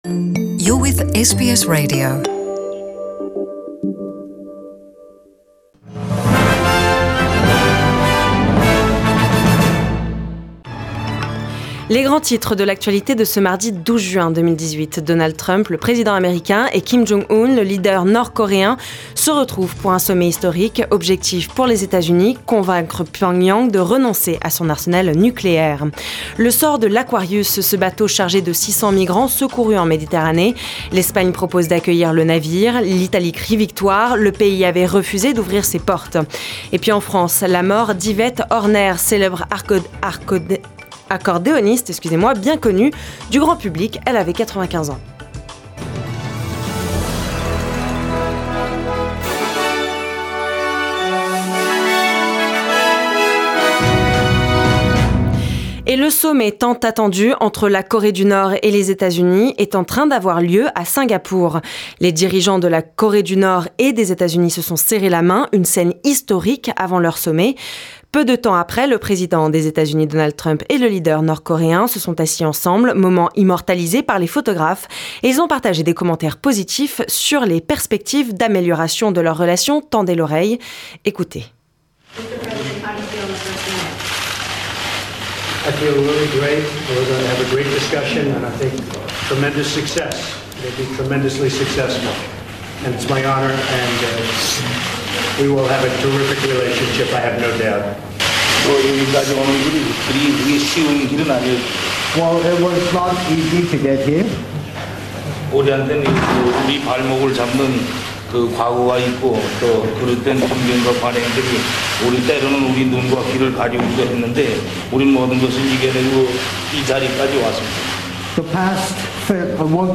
The news of the day, in Australia and in the world, in French language.